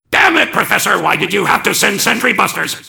mvm_sentry_buster_alerts02.mp3